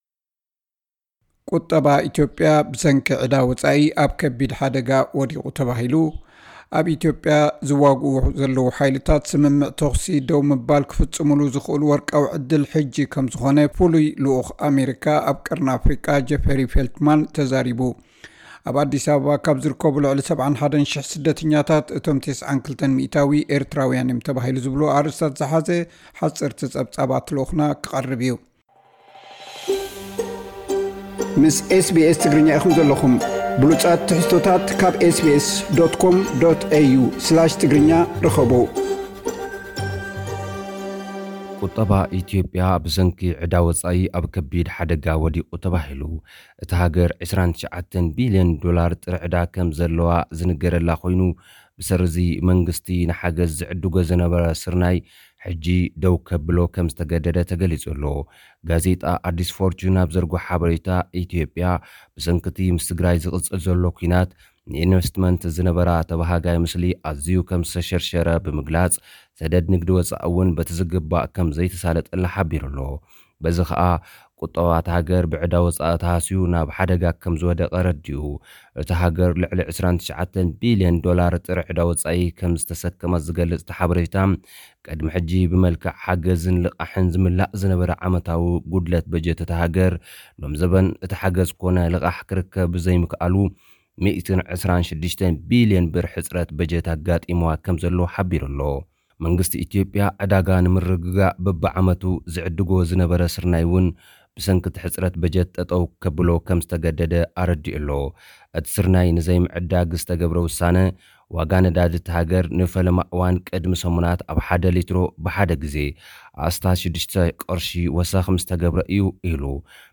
*ቁጠባ ኢትዮጵያ ብሰንኪ ዕዳ ወጻኢ ኣብ ከቢድ ሓደጋ ወዲቑ ተባሂሉ። *ኣብ ኢትዮጵያ ዝዋግኡ ዘለዉ ሓይልታት ስምምዕ ተኹሲ ደው ምባል ክፍጽሙሉ ዝኽእሉ ወርቃዊ ዕድል ሕጂ ከም ዝኾነ ፍሉይ ልኡኽ ኣሜሪካ ኣብ ቀርኒ ኣፍሪቃ ጀፍሪ ፌልትማን ተዛሪቡ። *ኣብ ኣዲስ ኣበባ ካብ ዝርከቡ ልዕሊ 71 ሽሕ ስደተኛታት እቶም 92% ኤርትራውያን እዮም ተባሂሉ።። ዝብሉ ኣርእስታት ዝሓዘ ሓጸርቲ ጸብጻባት ልኡኽና ክቐርብ’ዩ።